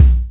Kicks
SW KCK4.wav